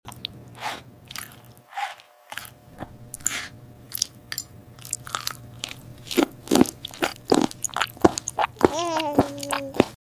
Strawberry Eating 🍓 ASMR Make sound effects free download